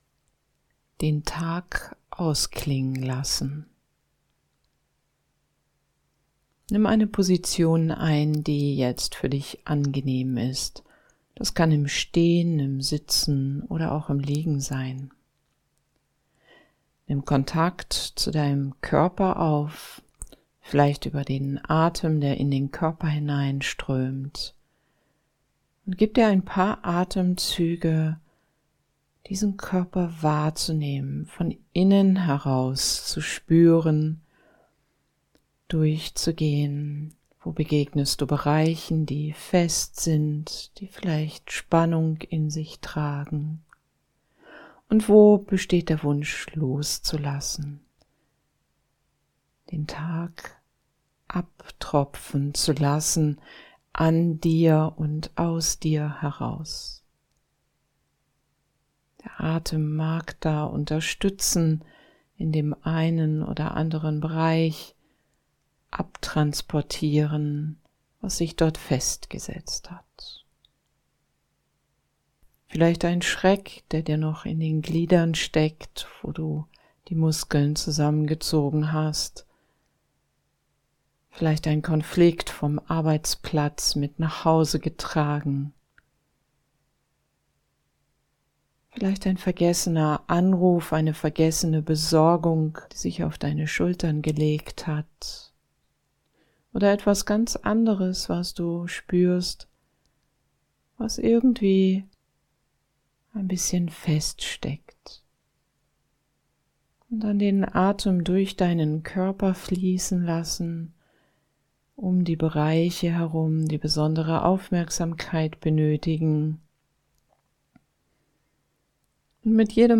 Es sind entspannende drei Minuten des Innehaltens, wenn du auf den Button klickst.